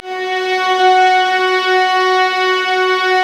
Index of /90_sSampleCDs/Roland - String Master Series/STR_Vlas Bow FX/STR_Vas Sul Pont